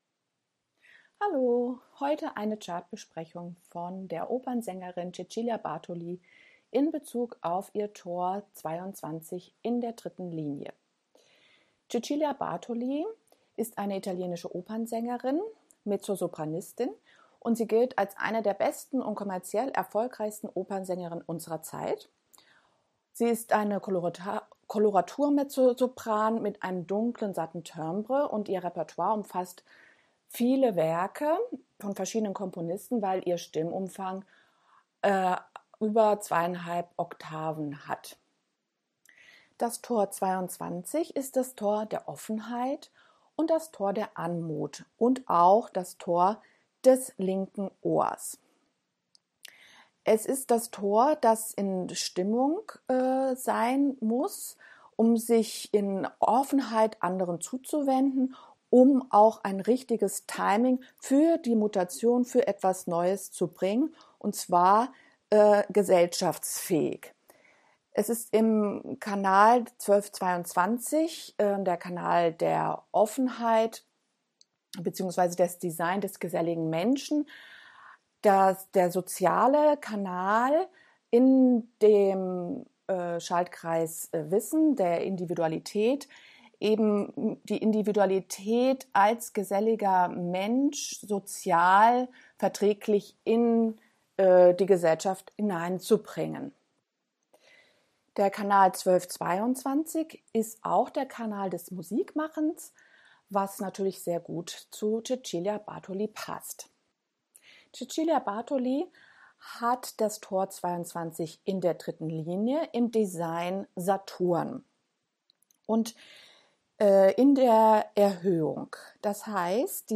Als Wiederholung heute eine Chartbesprechung der Opernsängerin Cecilia Bartoli in Bezug auf das Tor 22.3.